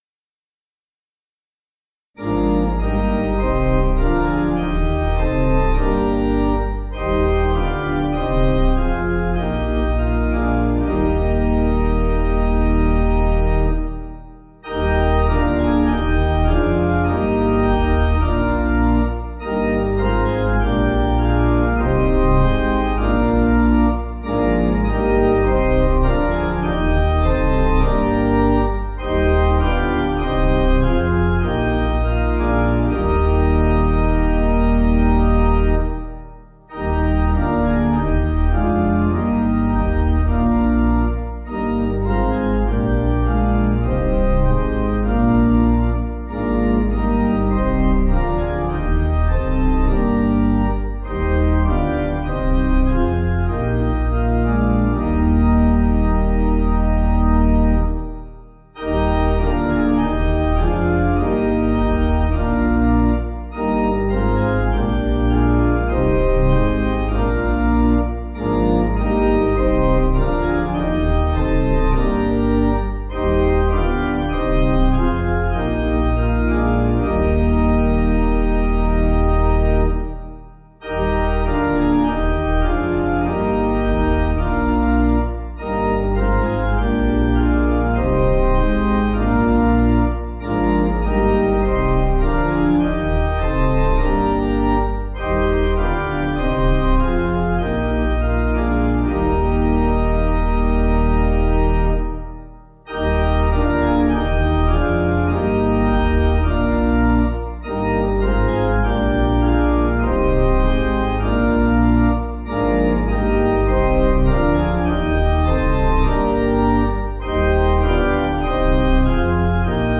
Organ
(CM)   6/Eb